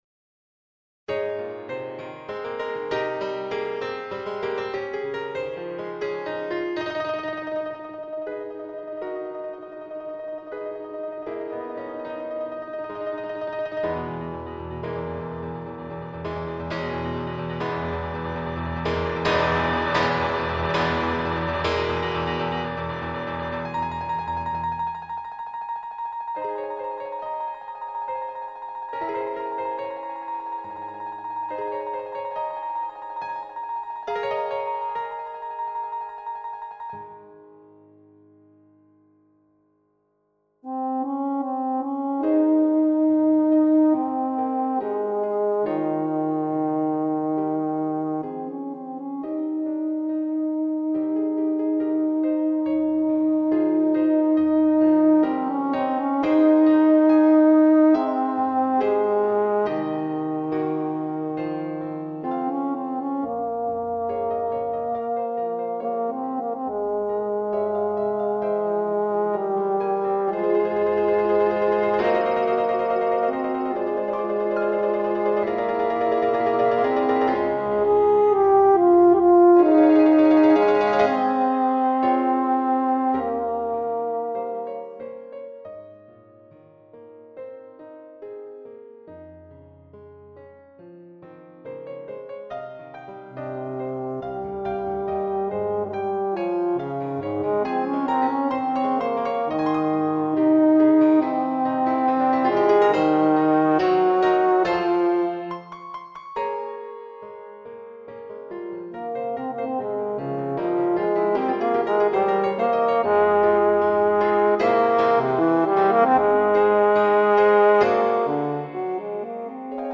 Single movement.